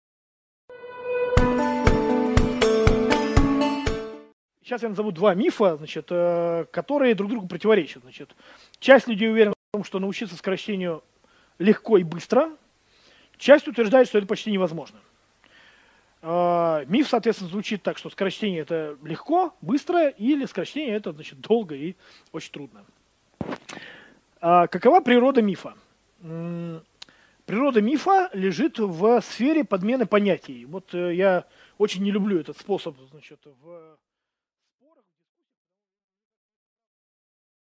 Aудиокнига Как научиться читать быстро? Легко ли научиться скорочтению?